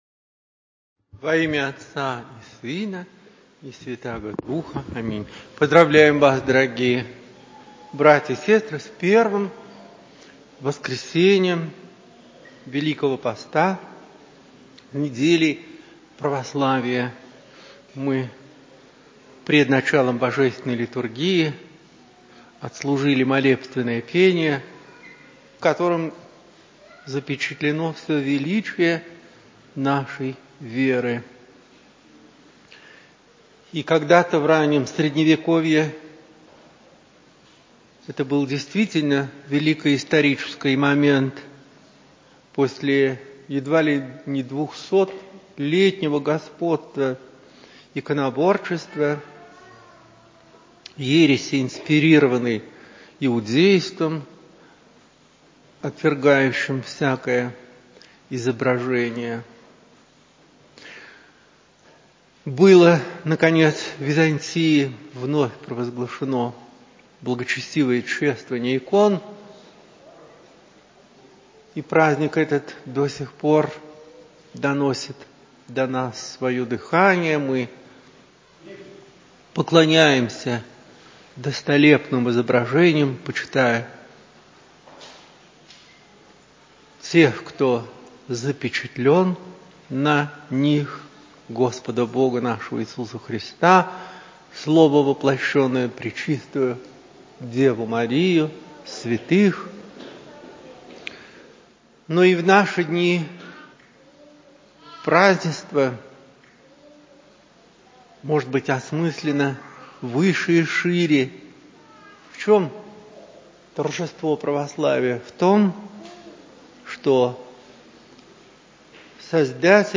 В храме Алексея человека Божия Алексеевского ставропигиального женского монастыря, 13 марта 2022.